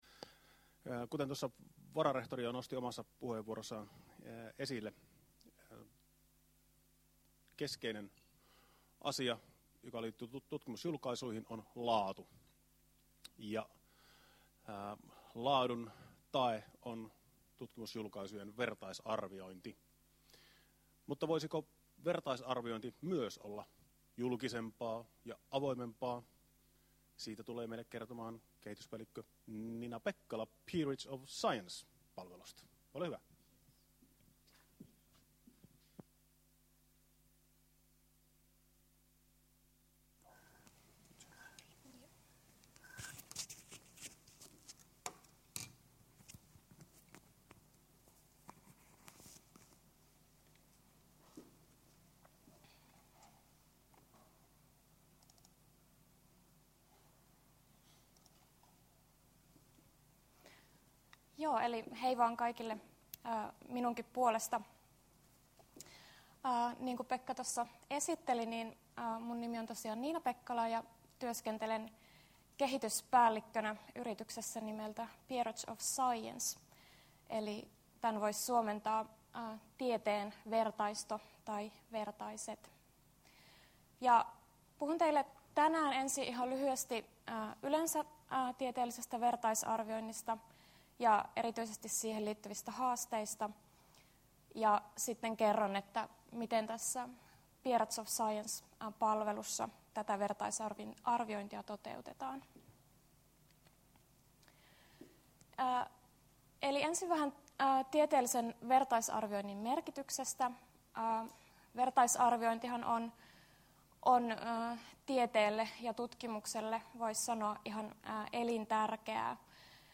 Tieteen julkisuus seminaarissa 12.4.2013 keskustellaan ajankohtaisista tieteelliseen julkaisutoimintaan liittyvistä kysymyksistä.